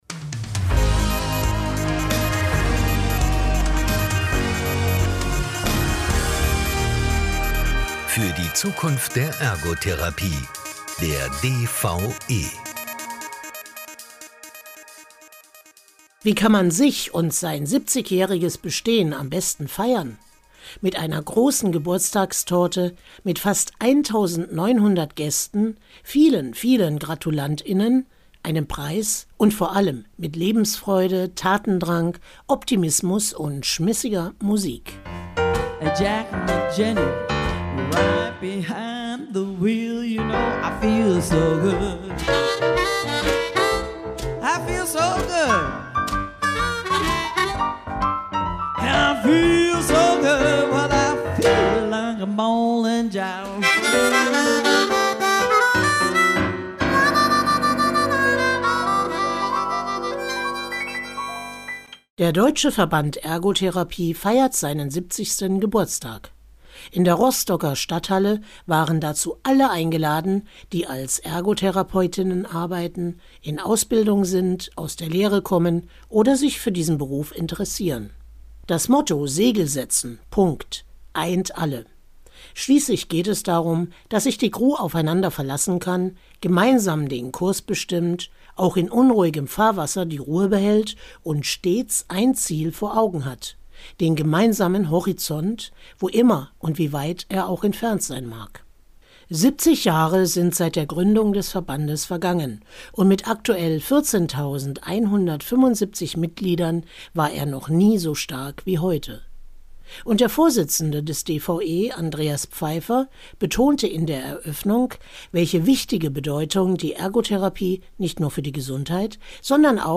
Der Verband feiert sein Jubiläum in Rostock. Im Podcast kommen einige Gäste zu Wort.